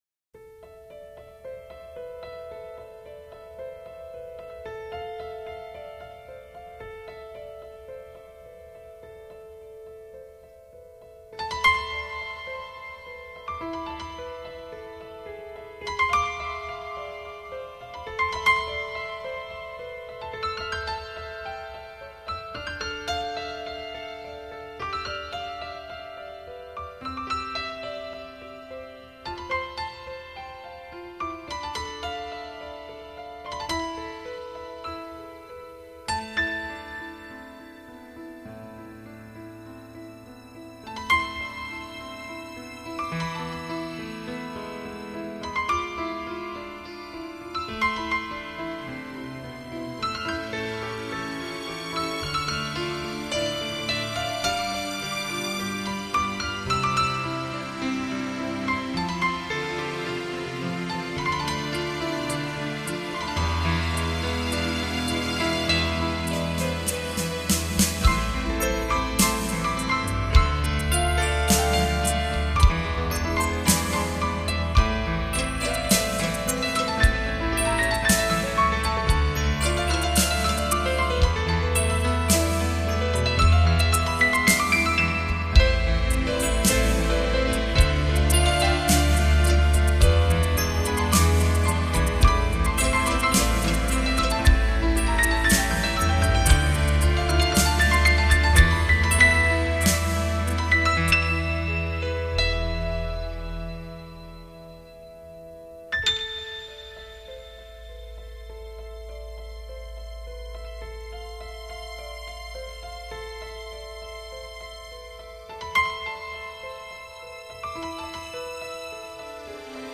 音乐类型：新世纪 钢琴